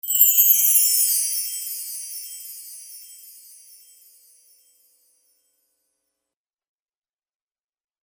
SFX – CHIME – C
SFX-CHIME-C.mp3